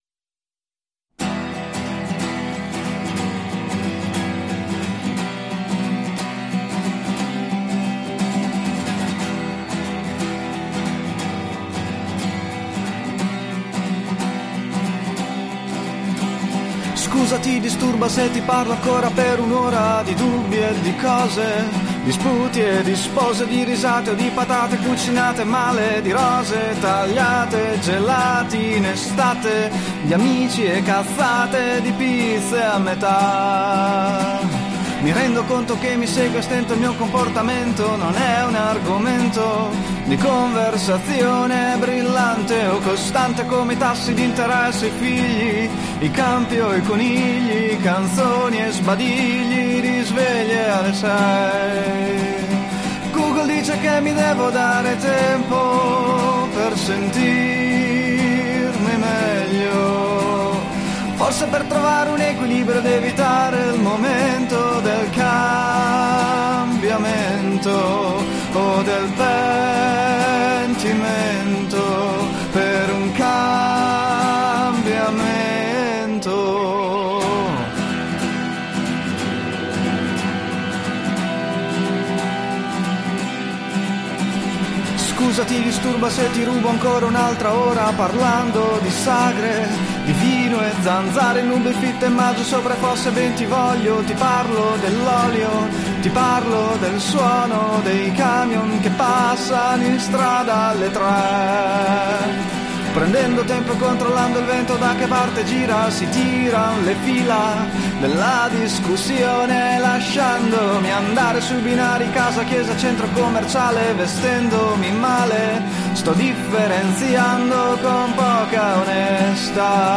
Luogo esecuzioneBologna